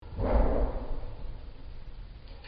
Loud Distant Banging Noise